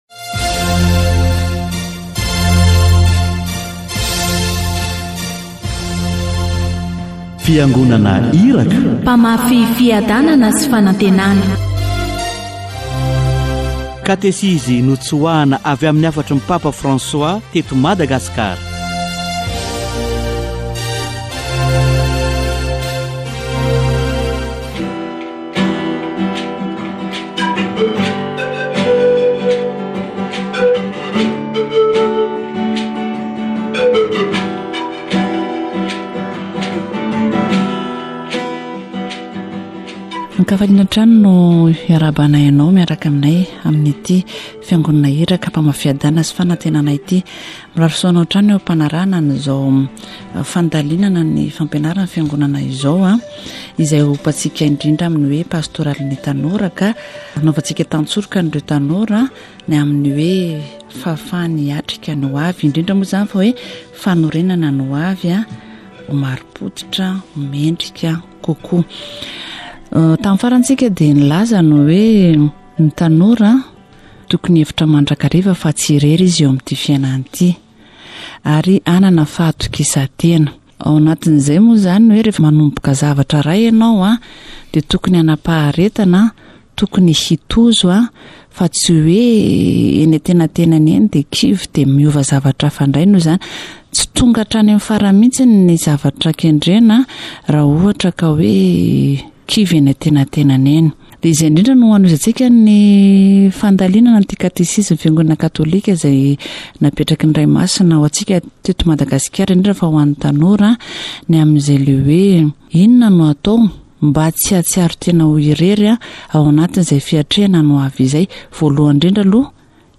Catechesis on personality